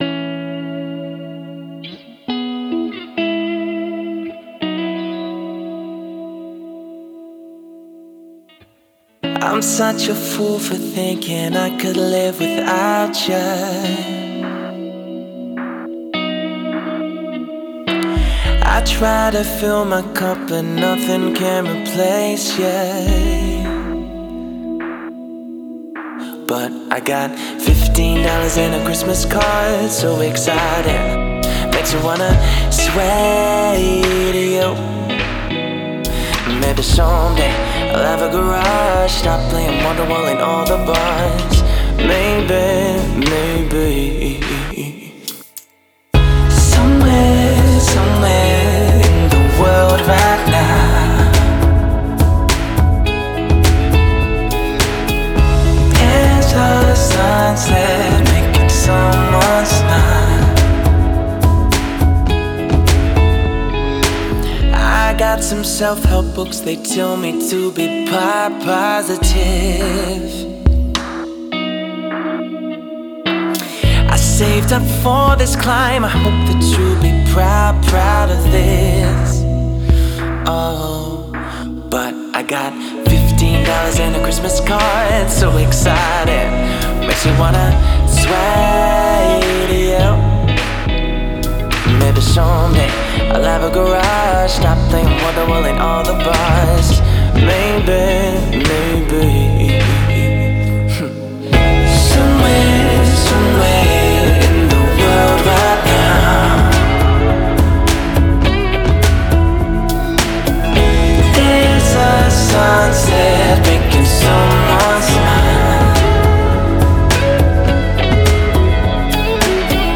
Guitars | Vocals | DJ | Trumpet | Looping